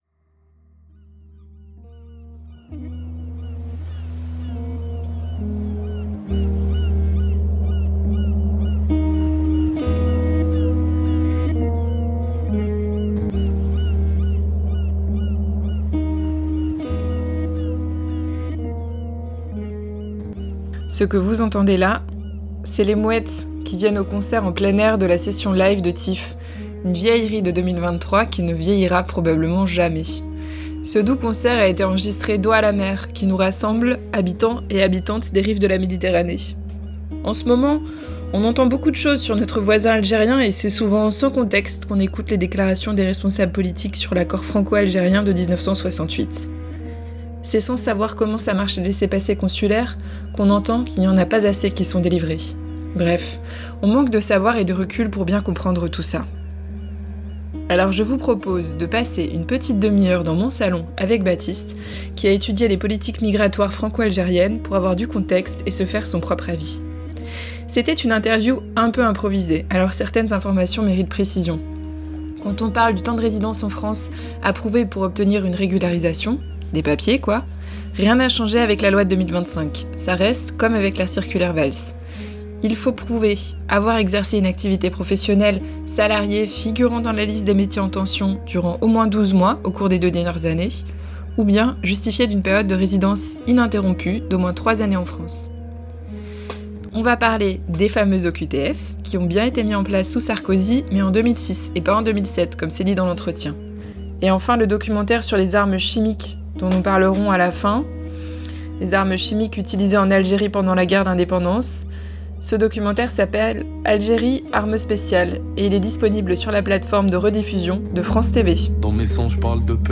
Entretien-accord-franco-algerien-1968.mp3